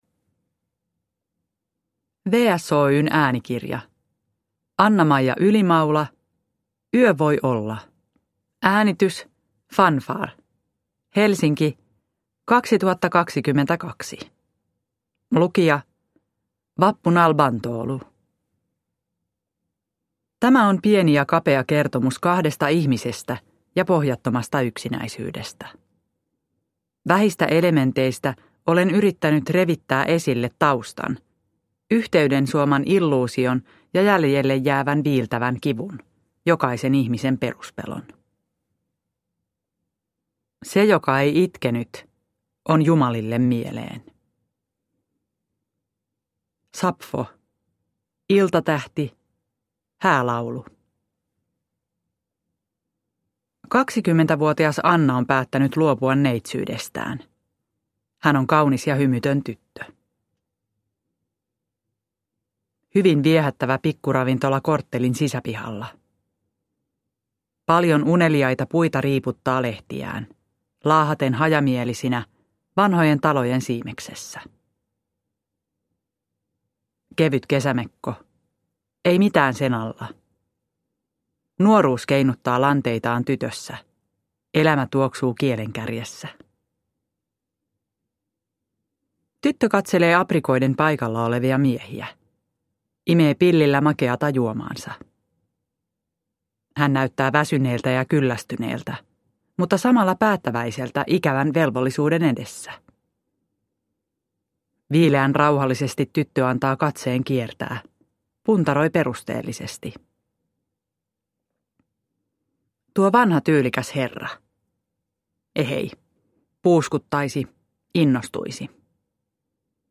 Yö voi olla – Ljudbok